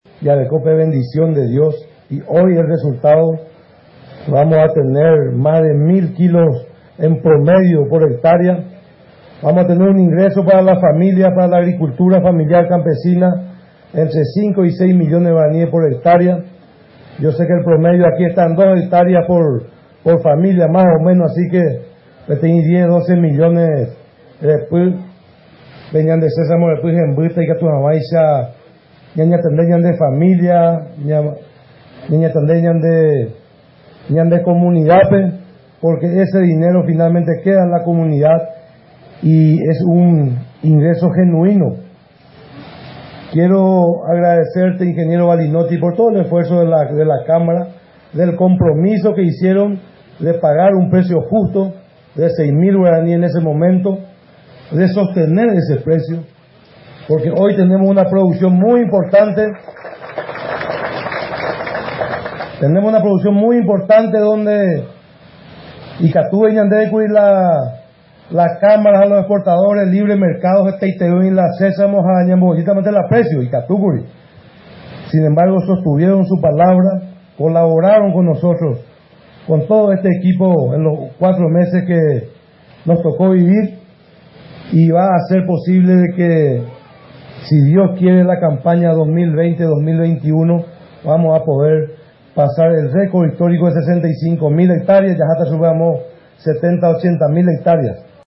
La cosecha de sésamo campaña 2019-2020, se inició este miércoles, durante un acto realizado en la compañía Felicidad del distrito de Liberación, departamento de San Pedro.
El ministro de Agricultura y Ganadería, Rodolfo Friedmann, expresó que existe una gran expectativa entre los productores.